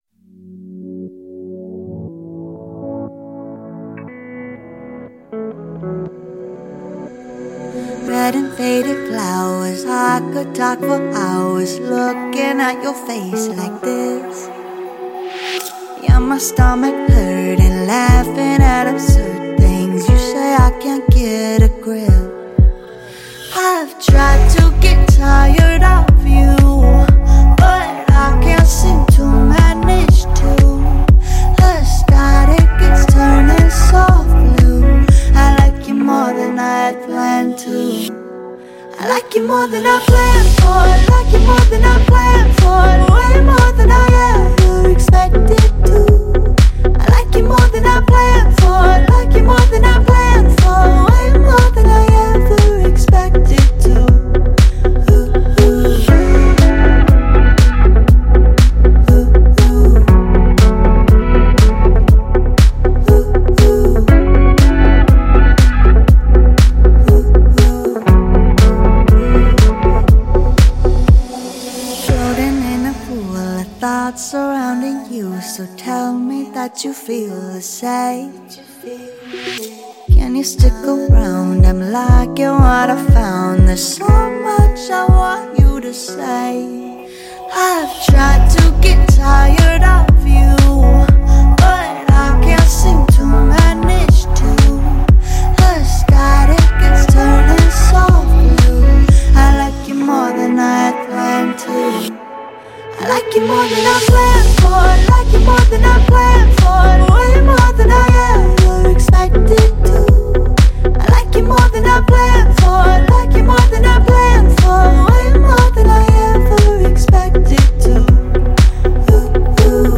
# indie pop # pop # funky # Electronic Pop # dance